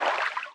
Sfx_creature_symbiote_swim_slow_06.ogg